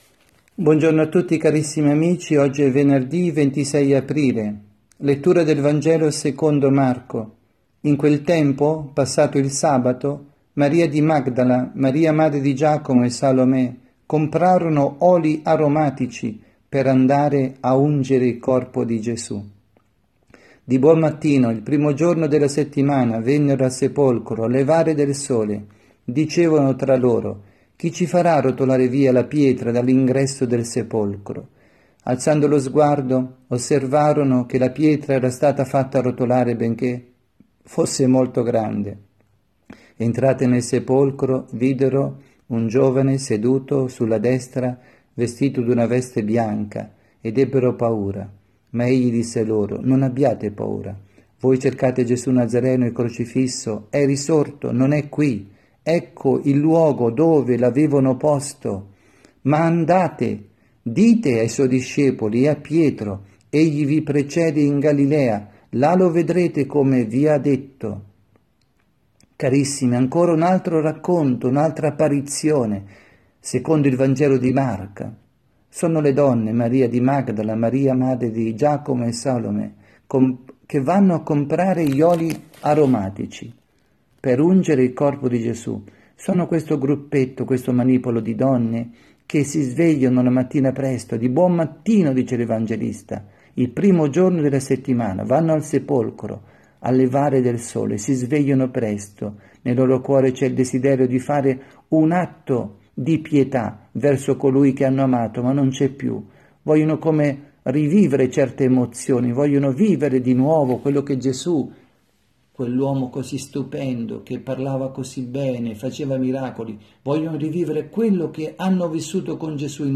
Omelia
dalla Casa di Riposo Santa Marta – Milano